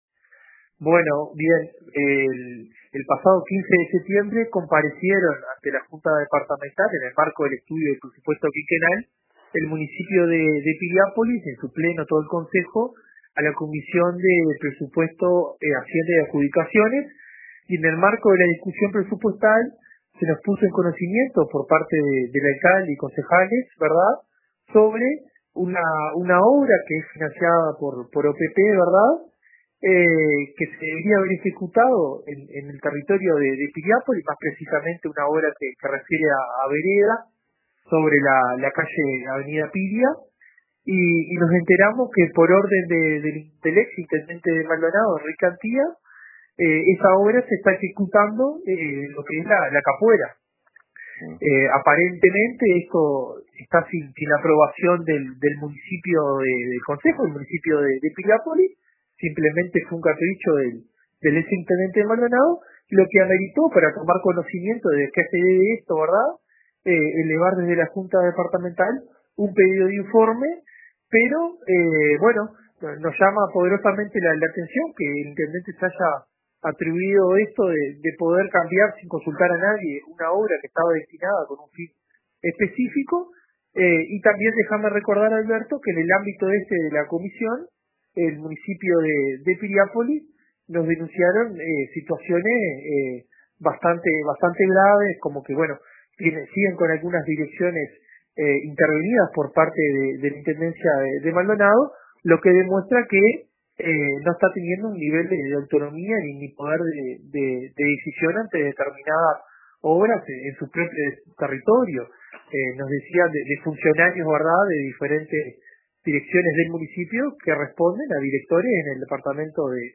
Una obra de veredas en Piriápolis, financiada por la OPP, se estaría ejecutando en La Capuera por orden del exintendente Enrique Antía, según informó el edil frenteamplista Juan Urdangaray a RADIO RBC. Urdangaray presentó un pedido de informes sobre el tema.